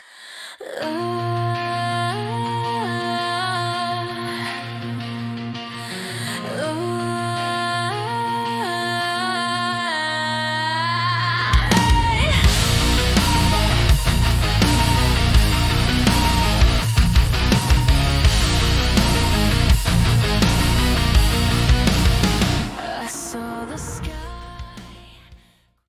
Style : Rock, New Metal